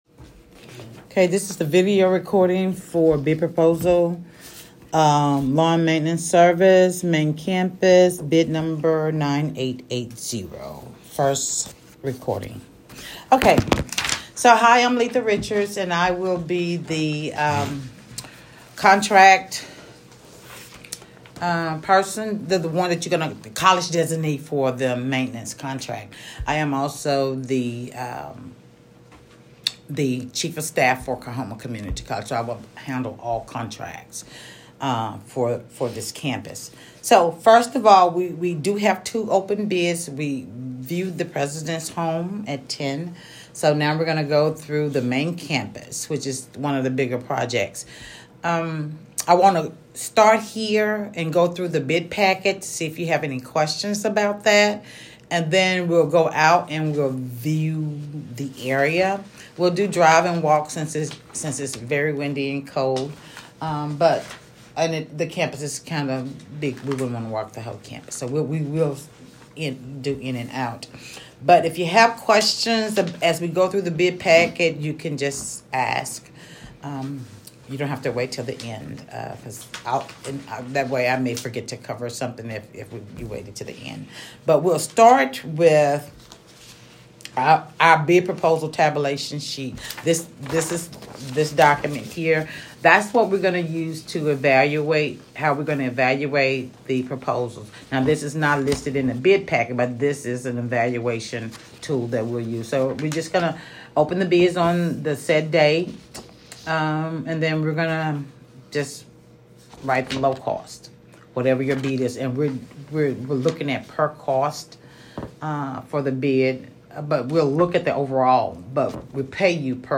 Pre-Bid Conference Audio-Recording
CCCBid_LawnCare_MainCampus_AudioofMeeting.m4a